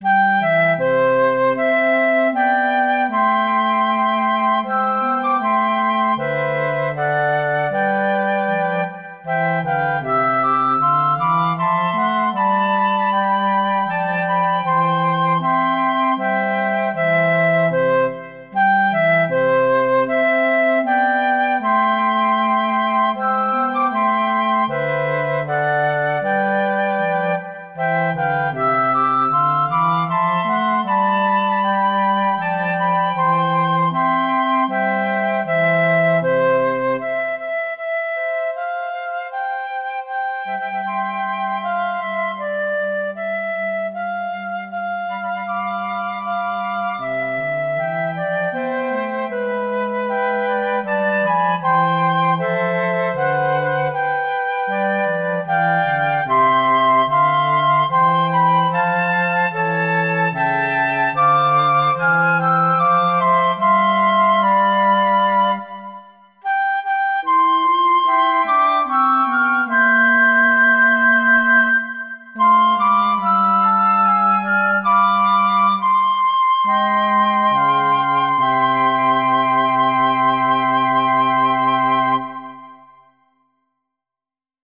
Voicing: Flute Quartet